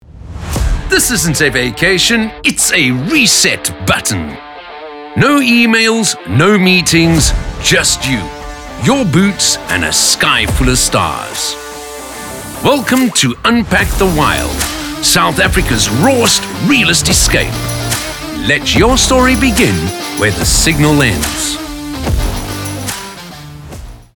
articulate, authentic, captivating, confident, Deep, energetic, friendly
30-45, 45 - Above
TV promo